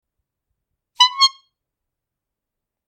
clownmove2.mp3